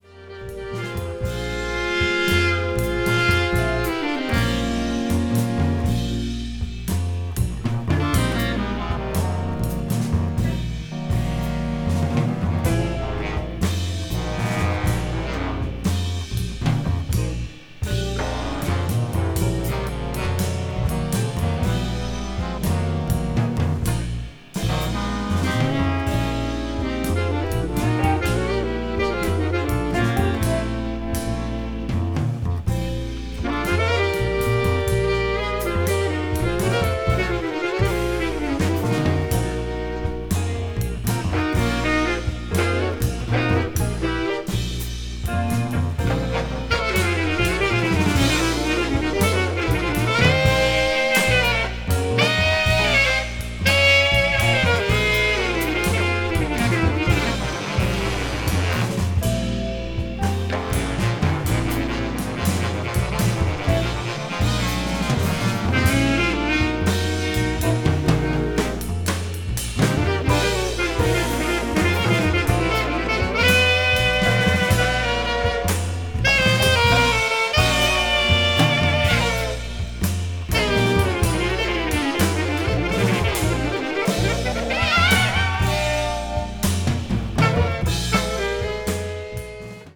avant-jazz   contemporary jazz   free jazz   jazz rock